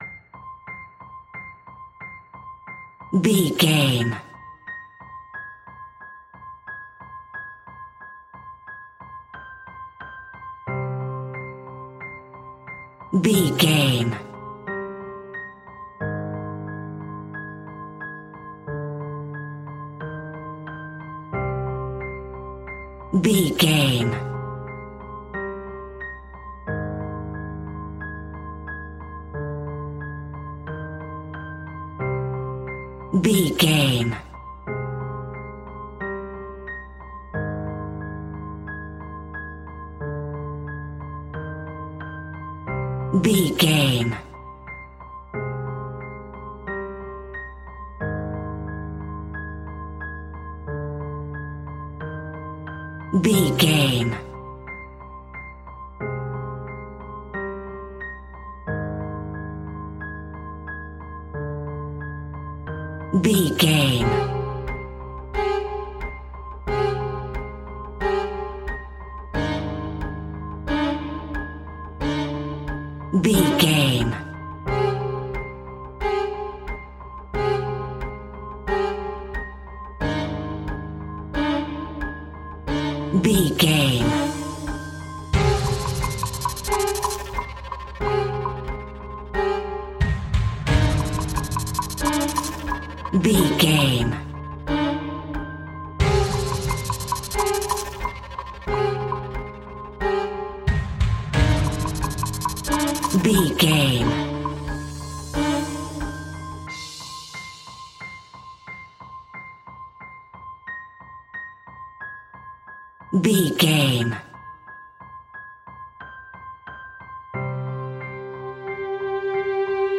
Aeolian/Minor
tension
ominous
eerie
piano
percussion
synthesizer
mysterious
horror music
Horror Pads